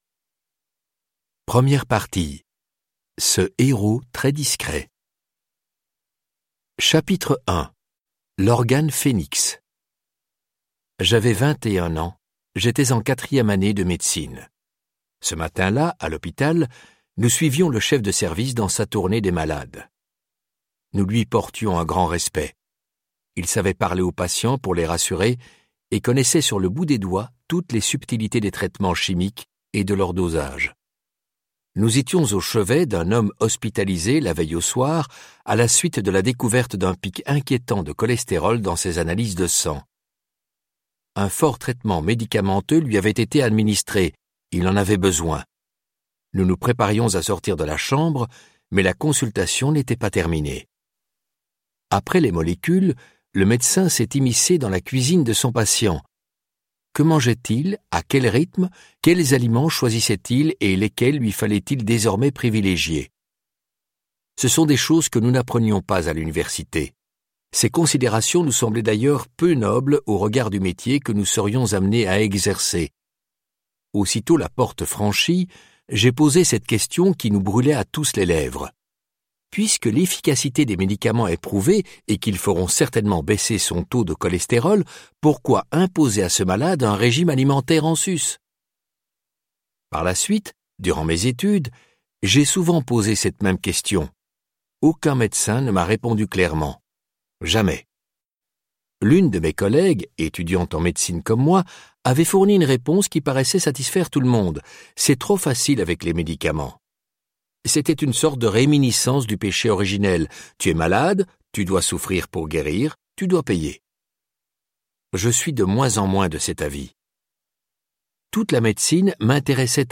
Avec ce livre audio, prenez soin de votre foie pour gagner des années de vie en bonne santé !Le foie, le plus volumineux de nos organes, est aussi le plus négligé.